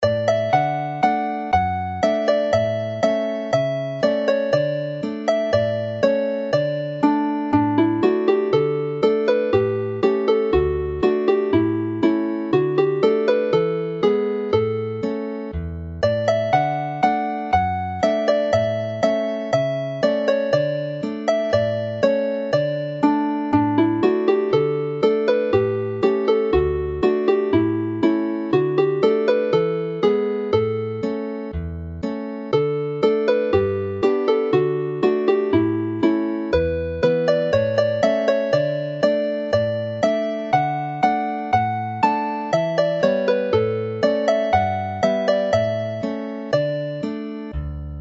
Play the melody slowly